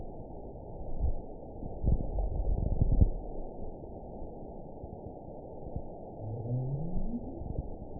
event 920736 date 04/07/24 time 02:53:42 GMT (1 year, 1 month ago) score 9.10 location TSS-AB03 detected by nrw target species NRW annotations +NRW Spectrogram: Frequency (kHz) vs. Time (s) audio not available .wav